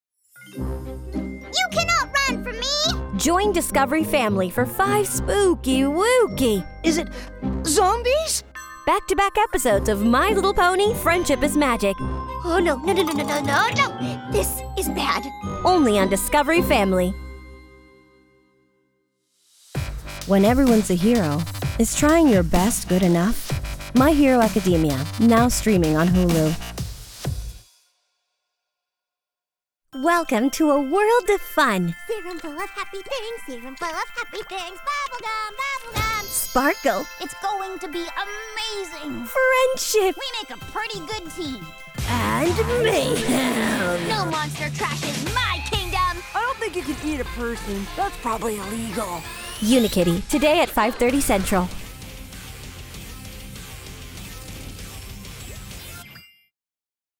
Child, Teenager, Young Adult, Adult
Has Own Studio
standard us | natural
tv promos